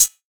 Hat (67).wav